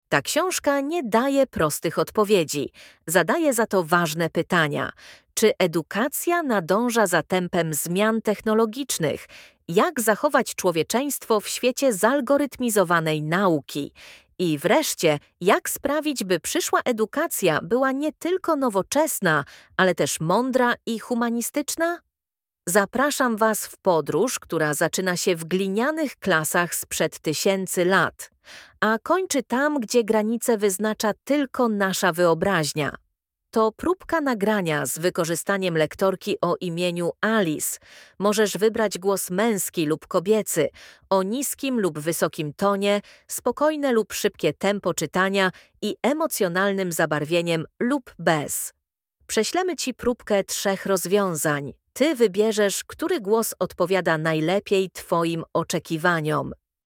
Nagrywanie audiobooków z wykorzystaniem inteligentnego oprogramowania to nowoczesny sposób tworzenia nagrań głosowych, w którym cała narracja powstaje bez udziału tradycyjnego lektora, za to z użyciem sztucznie wygenerowanego głosu o wysokiej jakości.